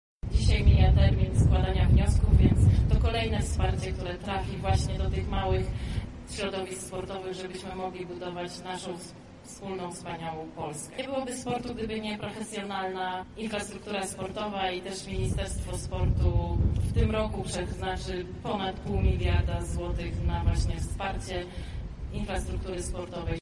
Mamy też nowy program „Sportowe Wakacje + – mówi Minister Sportu Danuta Dmowska-Andrzejuk: